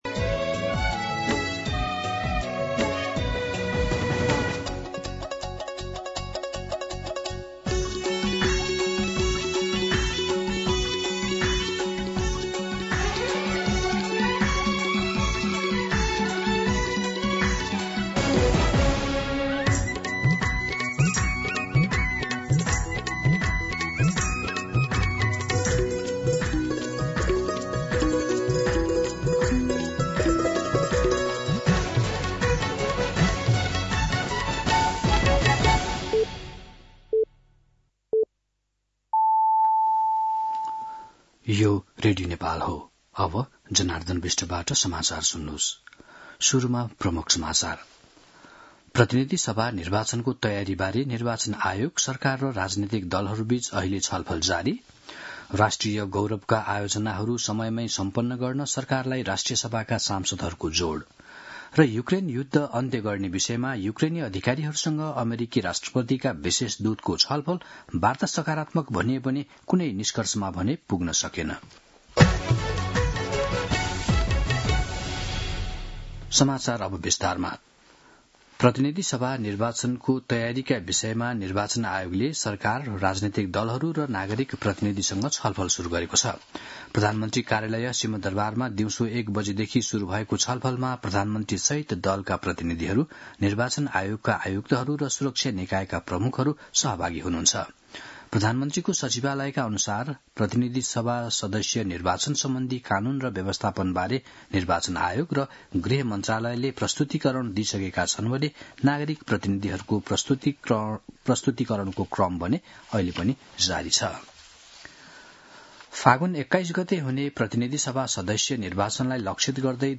दिउँसो ३ बजेको नेपाली समाचार : ७ पुष , २०८२